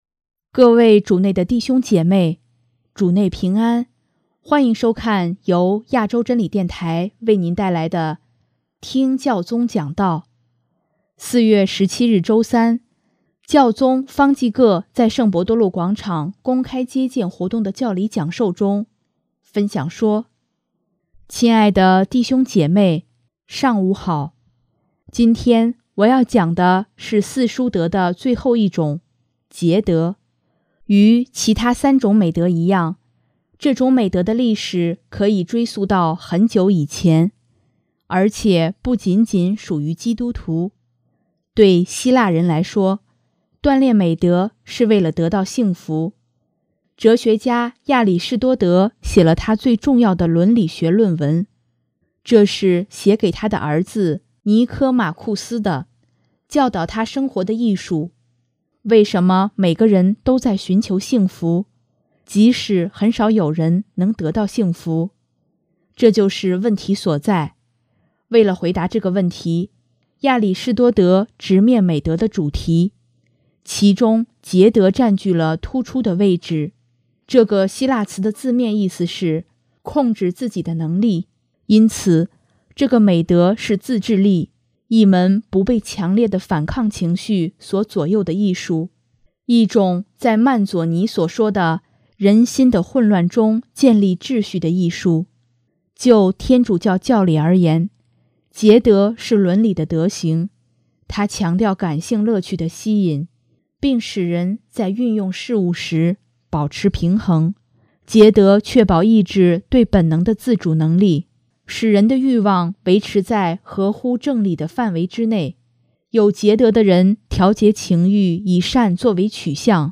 4月17日周三，教宗方济各在圣伯多禄广场公开接见活动的教理讲授中，分享说：